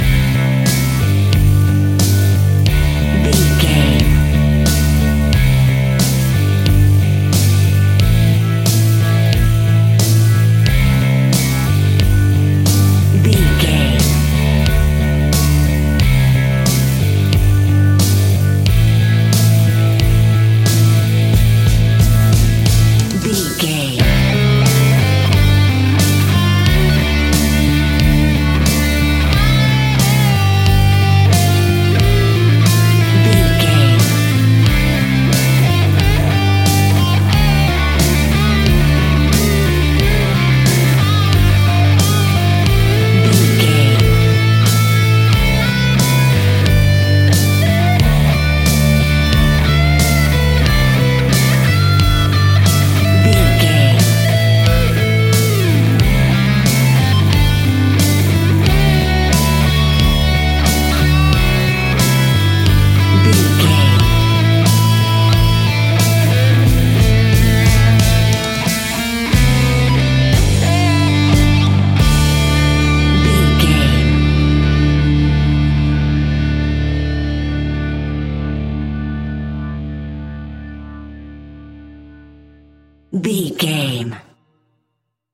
Mixolydian
hard rock
blues rock
Rock Bass
heavy drums
distorted guitars
hammond organ